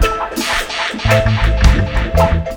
DUBLOOP 02-R.wav